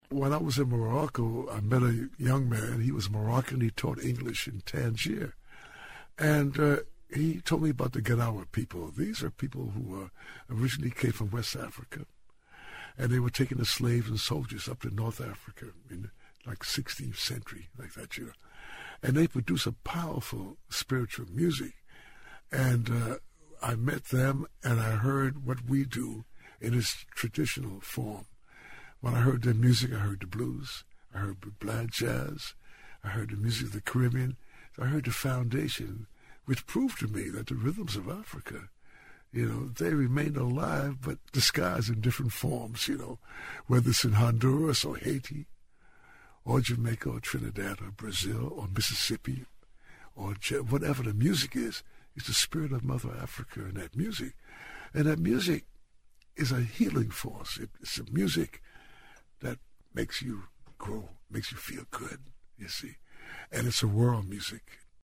In this excerpt from the podcast, Weston explains what he heard when he was first introduced to Gnawan music.